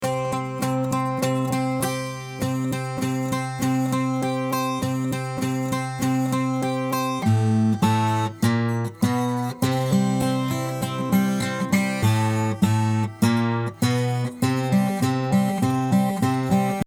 guitar00.mp3